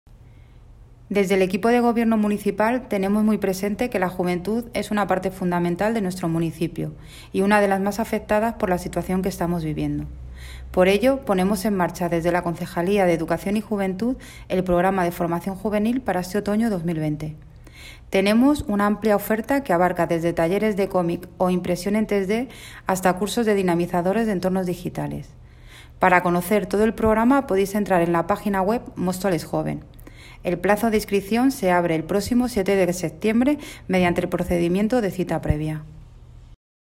Audio - Nati Gómez (Concejala de Educación y Juventud)